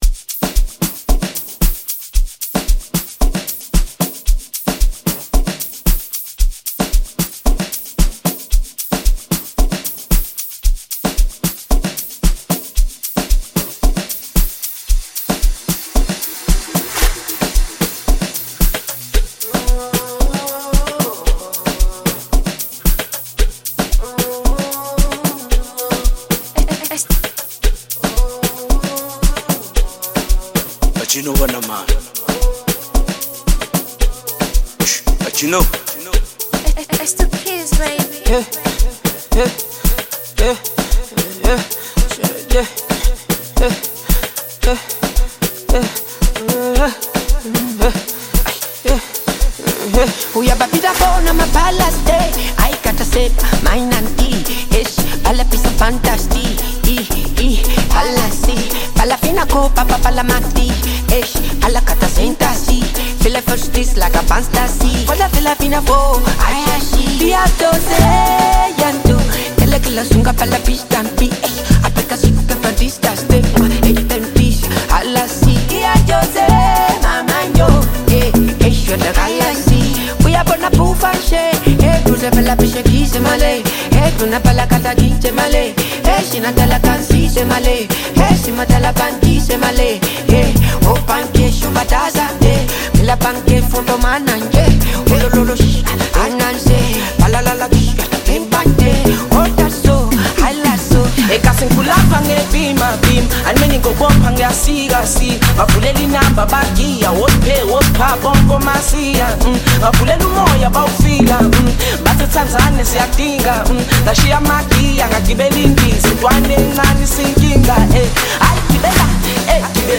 Tanzanian bongo flava artist
African Music